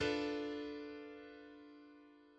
Dm6 D mineur sext d f a b[1]